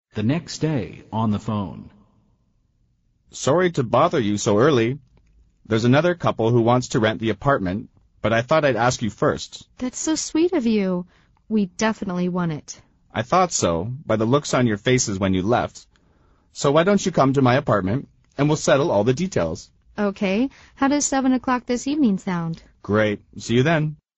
美语会话实录第151期(MP3+文本):That's so sweet of you